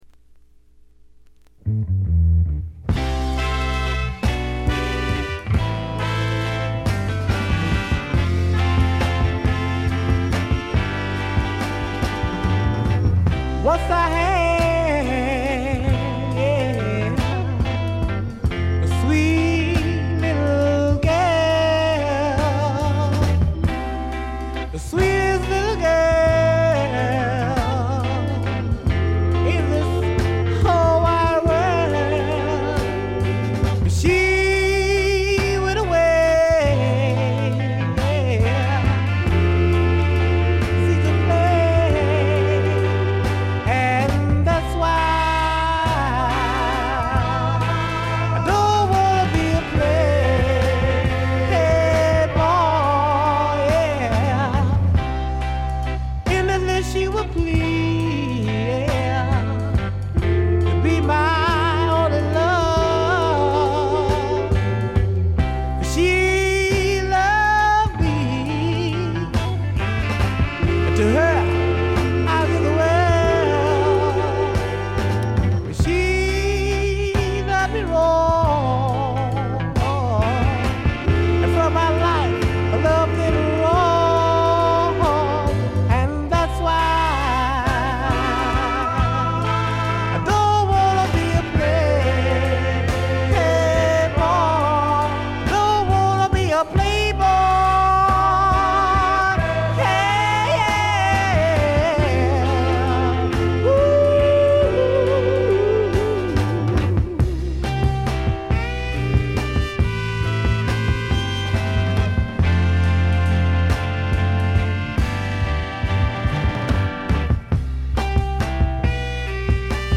ごくわずかなノイズ感のみ。
レア･グルーヴの極致！
試聴曲は現品からの取り込み音源です。